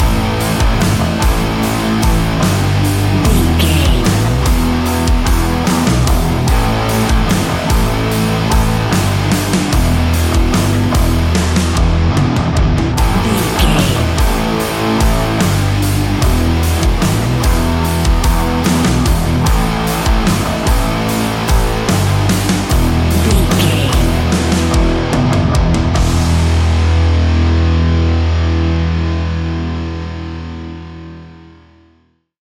Ionian/Major
E♭
hard rock
instrumentals